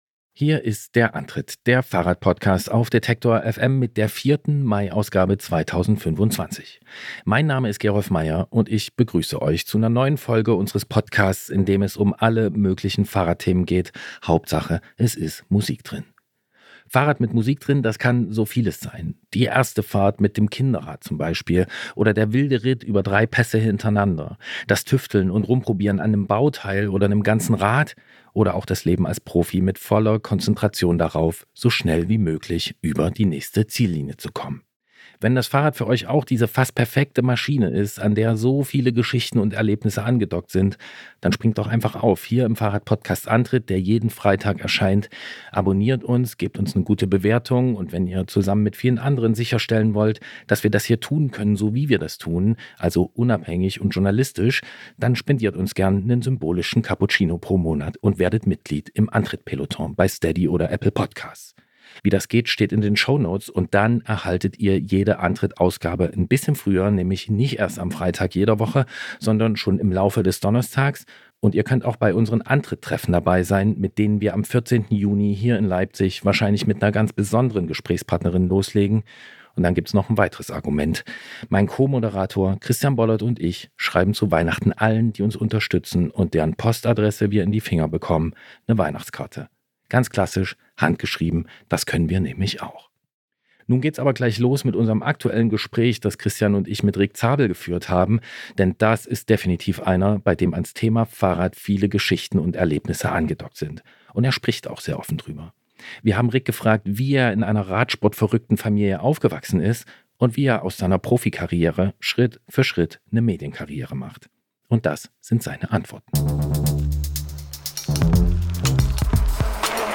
Interview: Wie wird man vom Profi zum Content Creator, Rick Zabel?